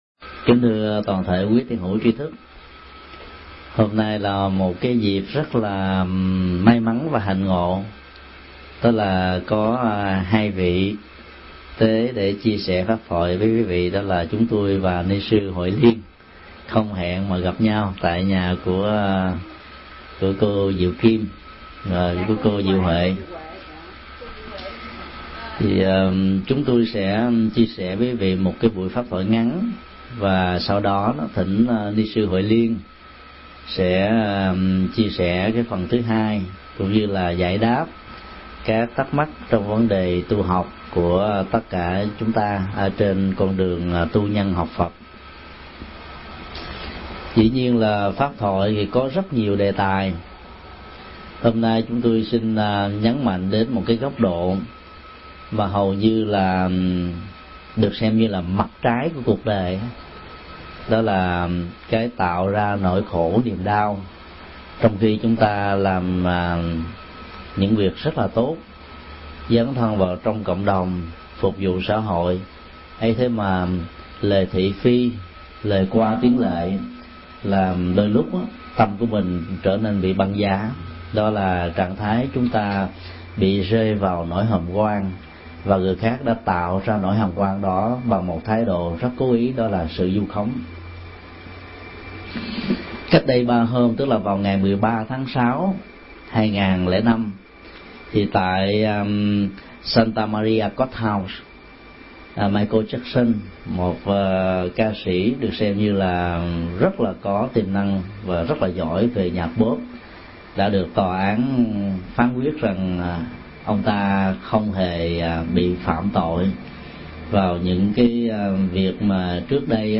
Mp3 Thuyết Pháp Vượt qua vu khống – Thầy Thích Nhật Từ Giảng tại Đạo tràng Diệu Huệ, San Francisco, ngày 16 tháng 6 năm 2005